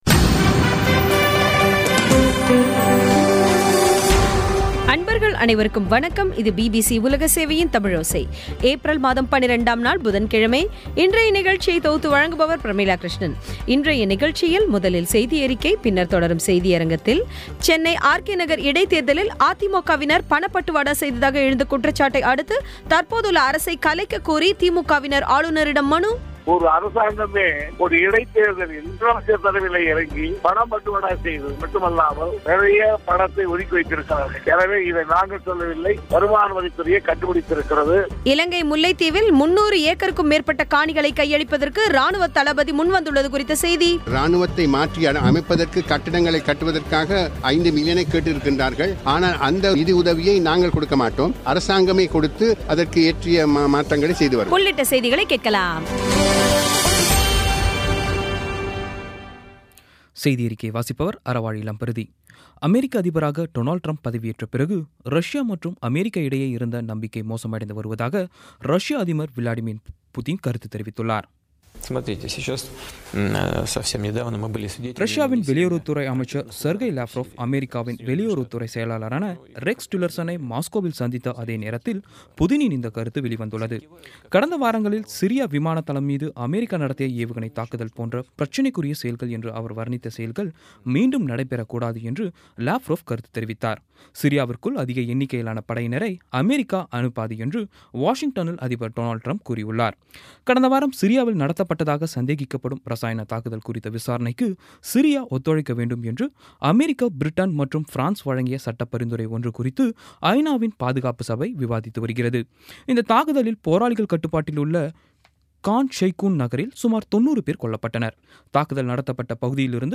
சென்னை ஆர்.கே. நகர் இடைத்தேர்தலில் அதிமுகவினர் பணப்பட்டுவாடா செய்ததாக எழுந்த குற்றச்சாட்டை அடுத்து தற்போதுள்ள அதிமுக அரசை கலைக்க வேண்டும் என்று கோரி ஆளுநரிடம் திமுக பிரதிநிதிகள் மனுகொடுத்தது குறித்த பேட்டி முல்லைத்தீவில் ராணுவத்தினர் நிலைகொண்டுள்ள பிரதேசத்தில் இருந்து 399 ஏக்கர் காணிகளைக் கையளிப்பதற்கு ராணுவத் தளபதி முன்வந்துள்ளது குறித்த செய்தி உள்ளிட்டவை கேட்கலாம்